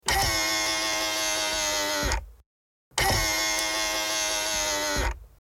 Звук поворотников этого автомобиля